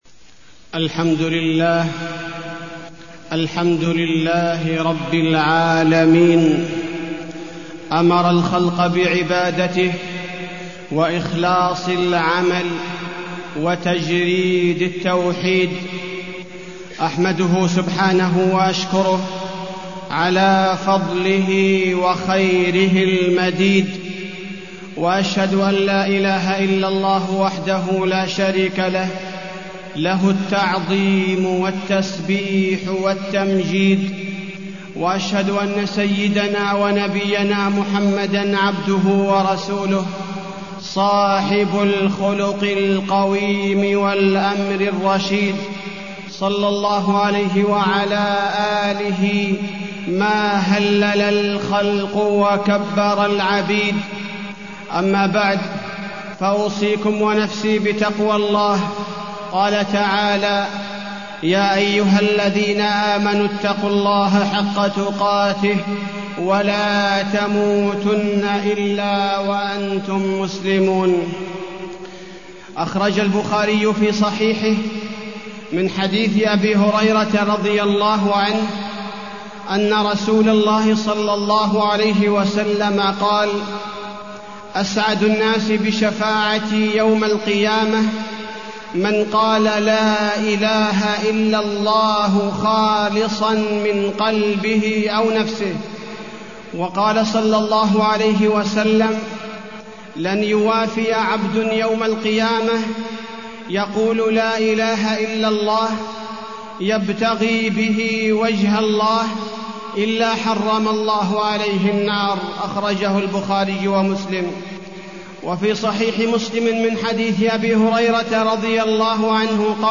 تاريخ النشر ٢١ شوال ١٤٢٠ هـ المكان: المسجد النبوي الشيخ: فضيلة الشيخ عبدالباري الثبيتي فضيلة الشيخ عبدالباري الثبيتي التوحيد